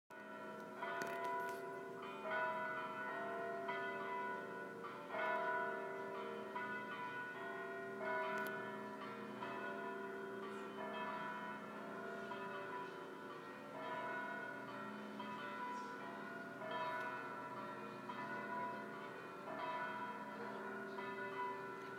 Campanades per la llibertat